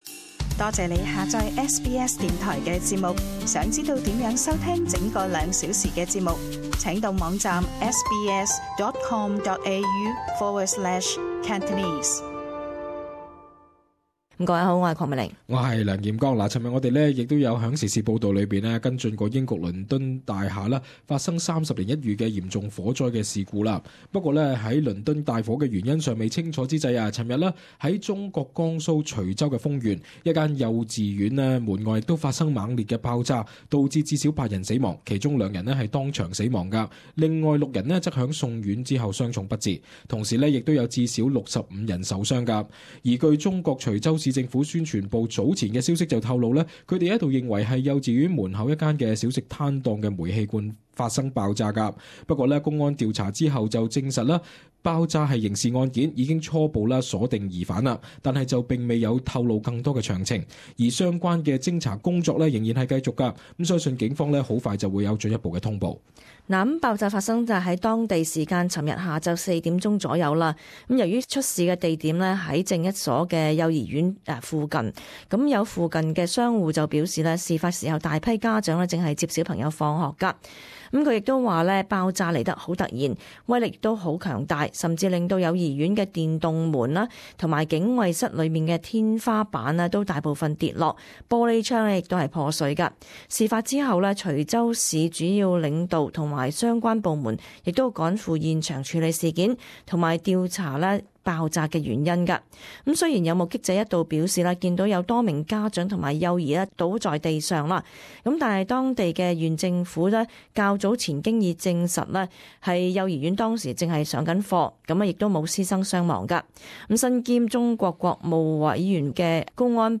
【时事报导】中国警方初步确认江苏幼儿园外爆炸案疑犯